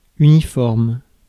Ääntäminen
US : IPA : [ˈlɪv.ə.ɹi] UK : IPA : /ˈlɪv.ɹɪ/ IPA : /ˈlɪv.ə.ɹɪ/